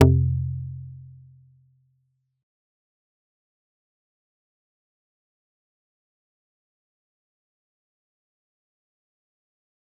G_Kalimba-F2-mf.wav